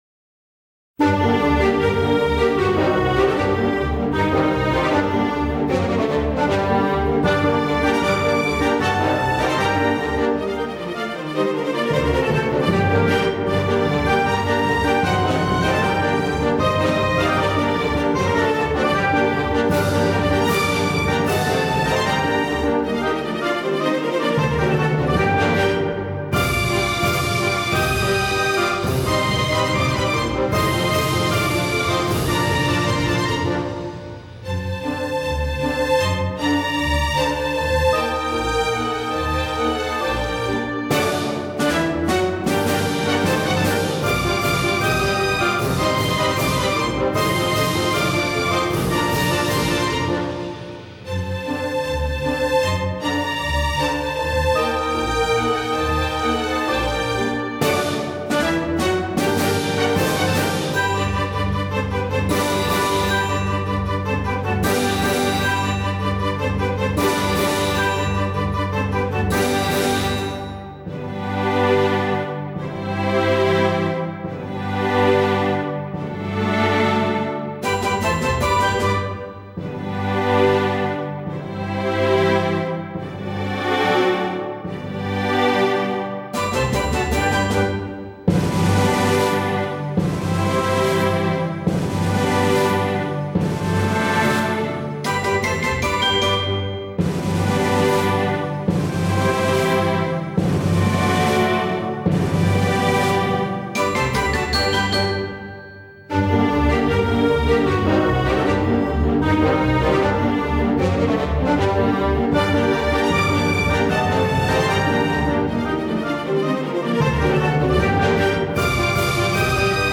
BPM35-156
Audio QualityPerfect (Low Quality)